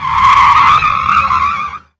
Tire Screech
Tires screeching on asphalt during hard braking with rubber friction and skid
tire-screech.mp3